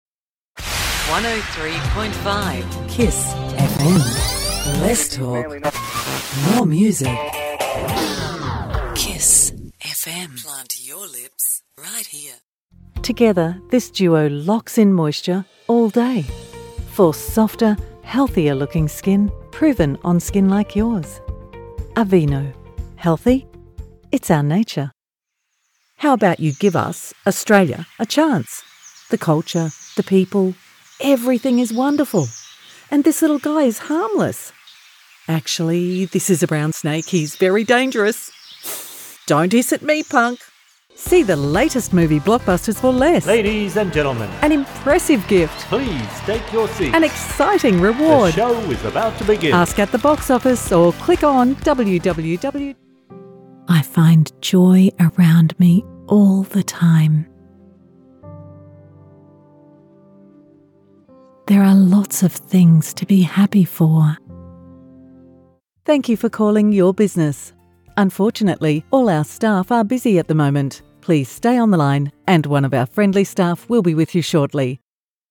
Female Voice Over Talent, Artists & Actors
Adult (30-50) | Older Sound (50+)